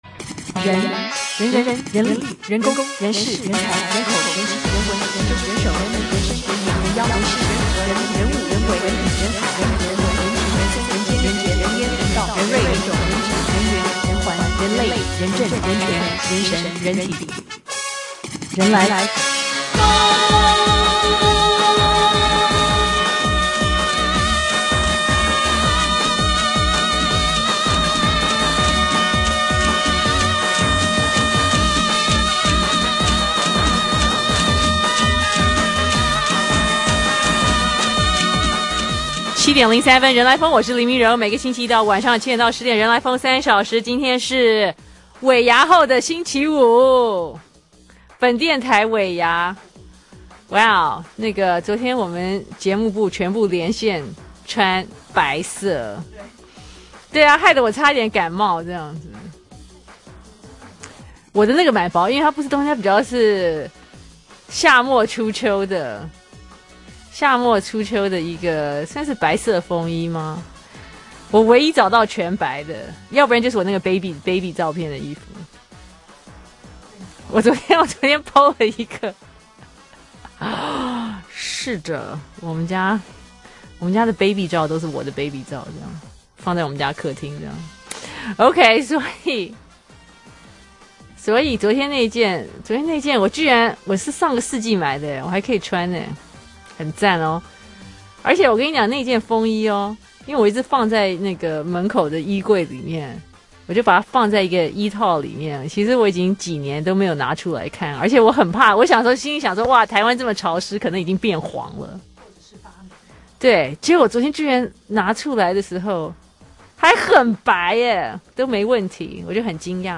專訪：李昂